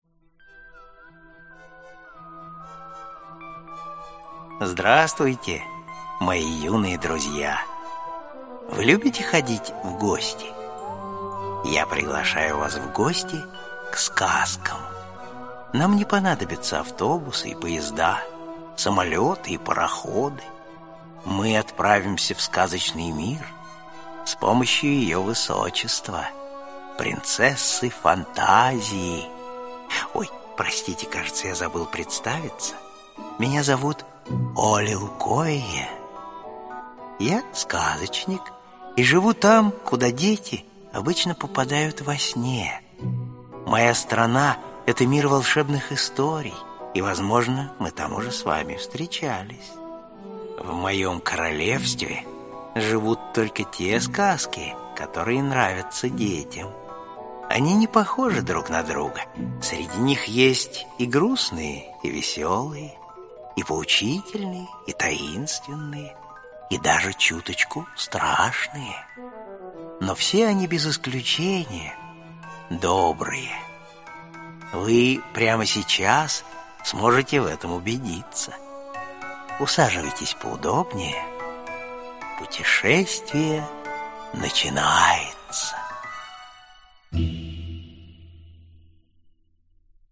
Аудиокнига Дикие лебеди | Библиотека аудиокниг
Aудиокнига Дикие лебеди Автор Ганс Христиан Андерсен Читает аудиокнигу Борис Плотников.